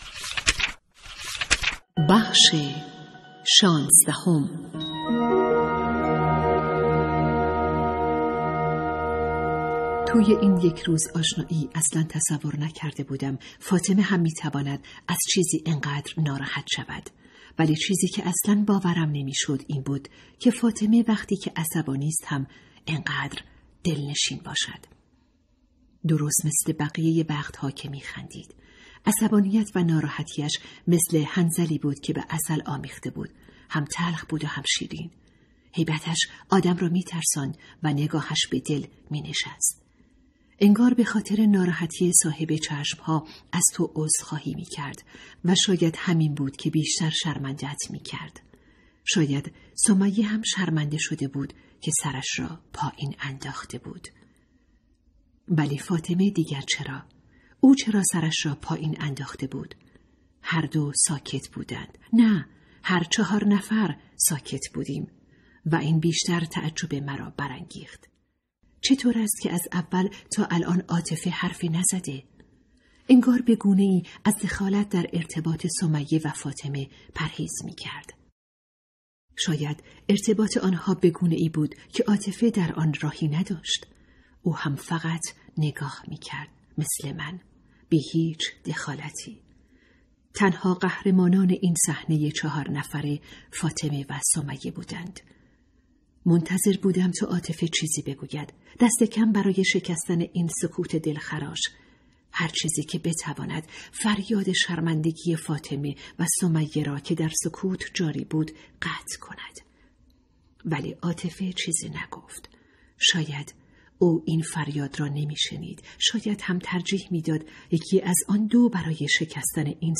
کتاب صوتی | دختران آفتاب (16)